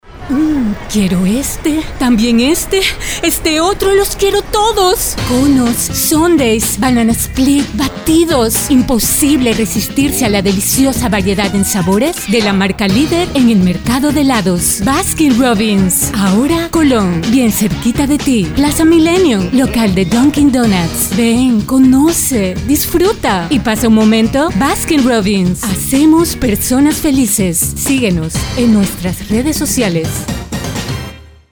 locutora de voz sensual,tono grave,medio y agudo,para publicidad,audiobooks, e learning,documentales,películas,otros.
kastilisch
Sprechprobe: Sonstiges (Muttersprache):